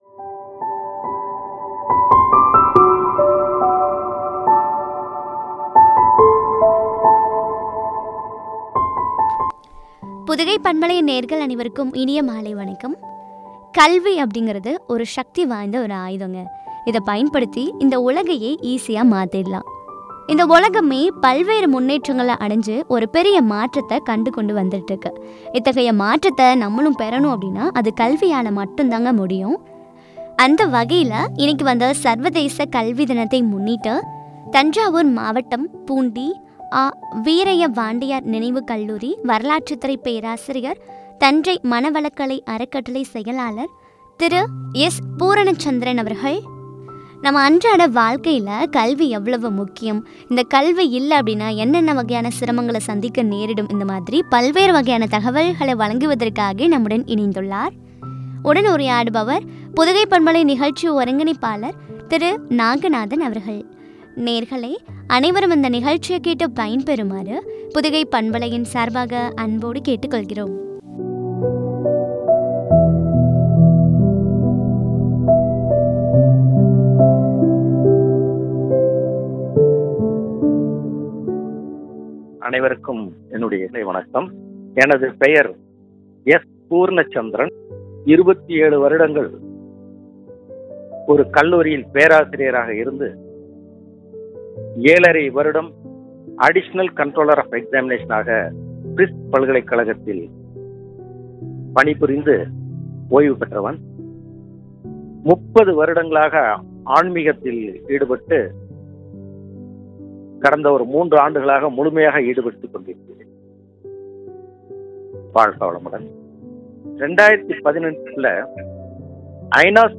“கல்வியால் மறுமலர்ச்சி” குறித்து வழங்கிய உரையாடல்.